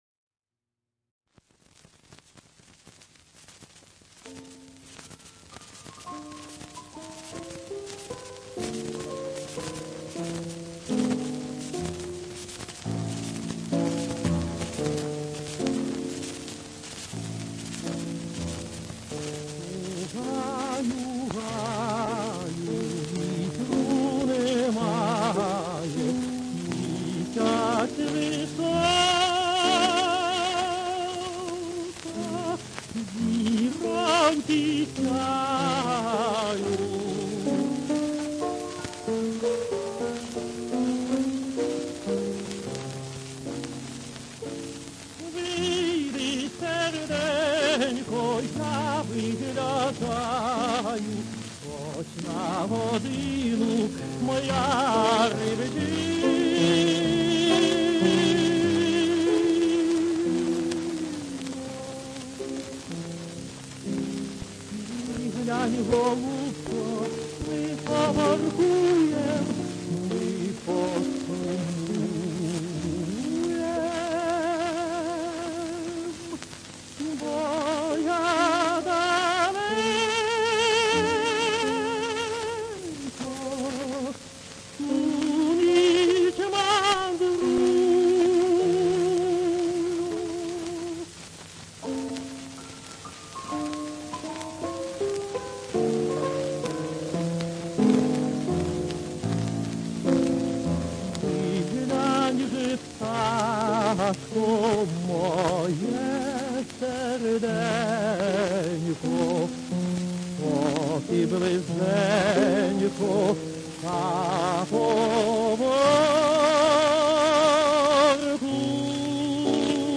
Песня «У гаю